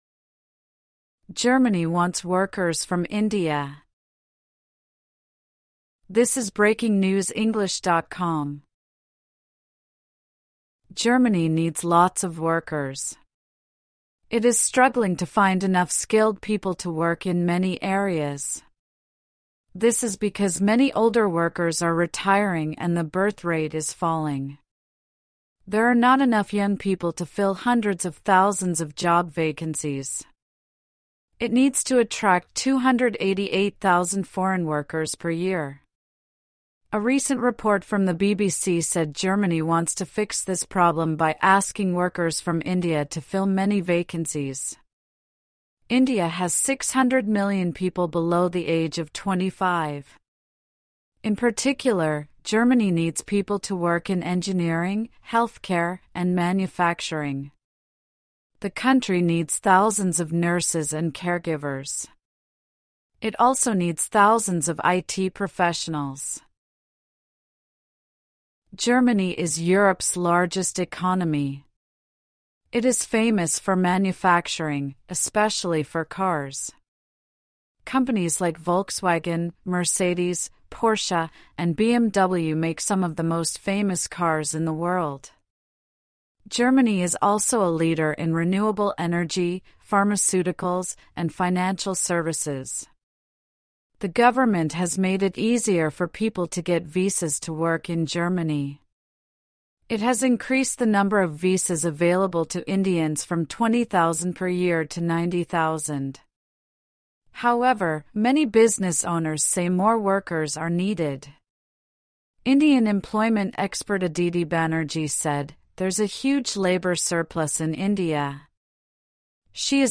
AUDIO(Noraml)